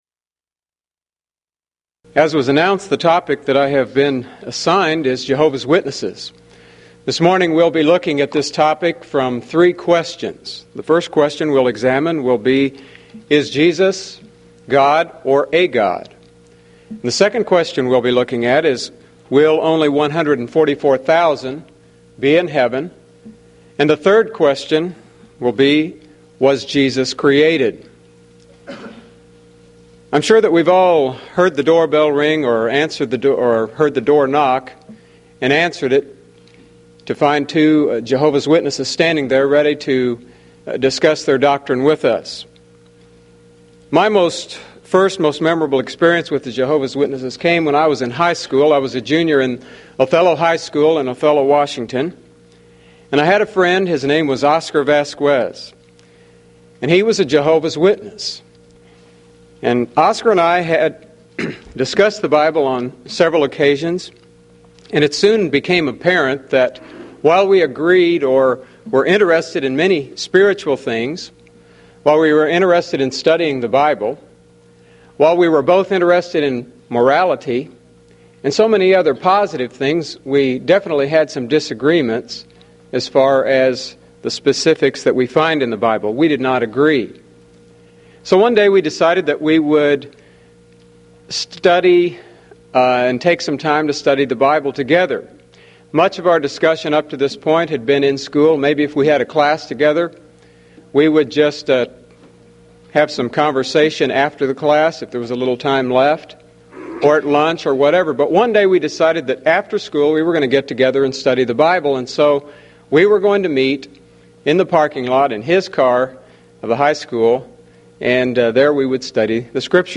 Event: 1995 Mid-West Lectures Theme/Title: The Twisted Scriptures